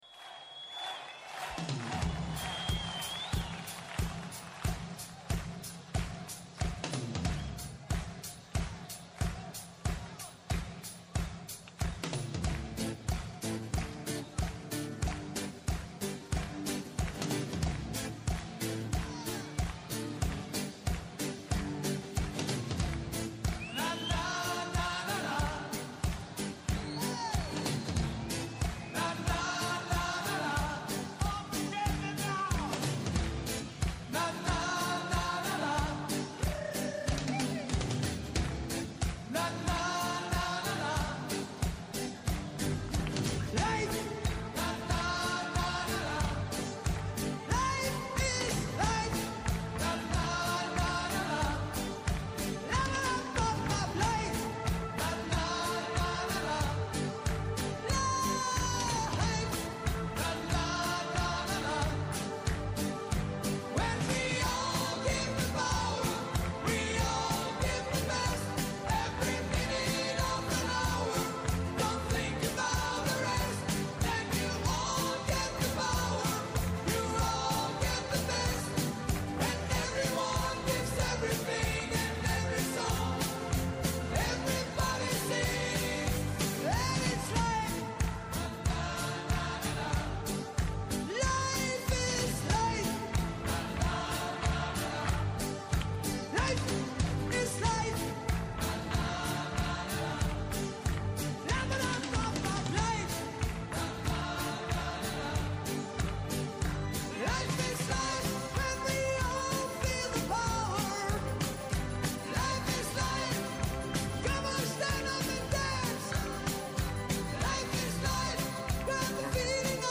Συμφωνούν, διαφωνούν, φωτίζουν και αποκρυπτογραφούν τα γεγονότα με πολύ κέφι, πολλή και καλή μουσική και πολλές εκπλήξεις. Με ζωντανά ρεπορτάζ από όλη την Ελλάδα, με συνεντεύξεις με τους πρωταγωνιστές της επικαιρότητας, με ειδήσεις από το παρασκήνιο, πιάνουν τιμόνι στην πρώτη γραμμή της επικαιρότητας.